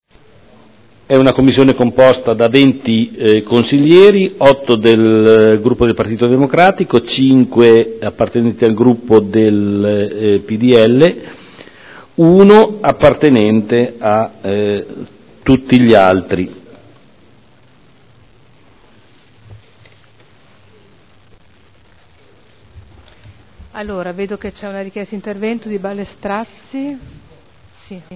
Seduta del 12 dicembre Commissione consiliare permanente Affari Istituzionali – Modifica